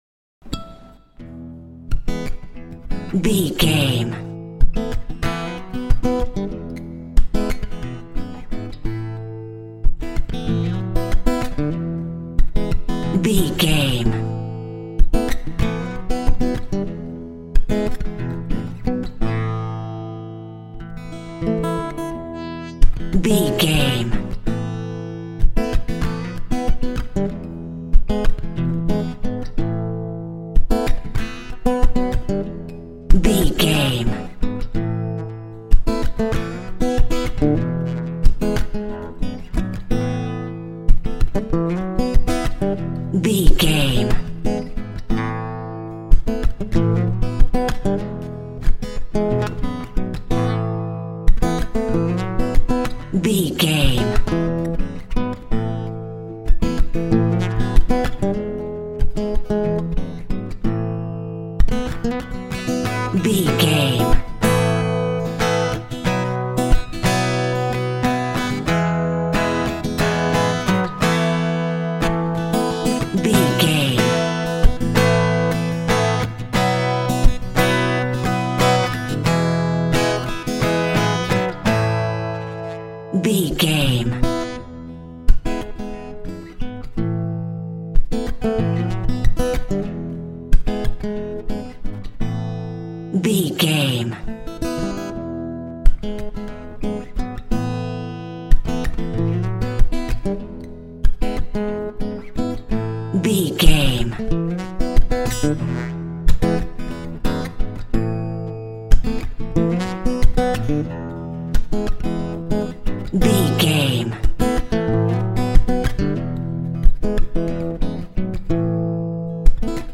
Slow Acoustic Ballad Cue.
Ionian/Major
WHAT’S THE TEMPO OF THE CLIP?
indie pop
pop rock
drums
bass guitar
electric guitar
piano
hammond organ